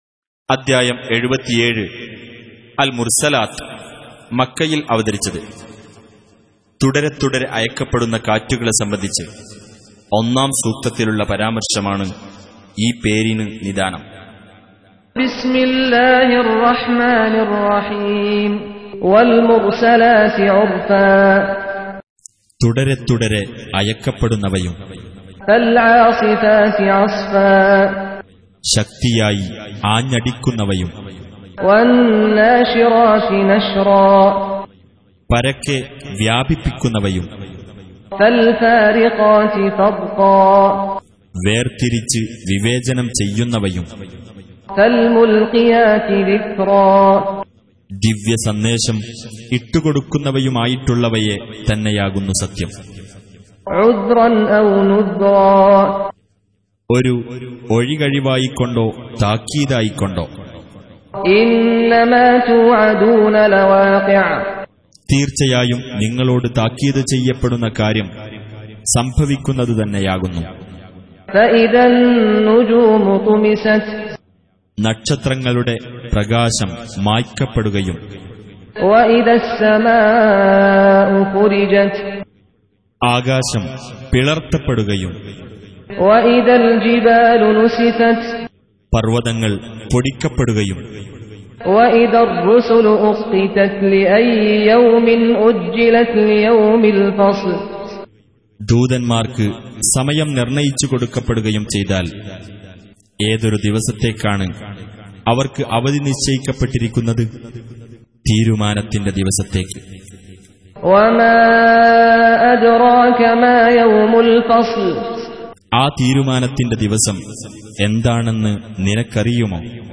Surah Repeating تكرار السورة Download Surah حمّل السورة Reciting Mutarjamah Translation Audio for 77. Surah Al-Mursal�t سورة المرسلات N.B *Surah Includes Al-Basmalah Reciters Sequents تتابع التلاوات Reciters Repeats تكرار التلاوات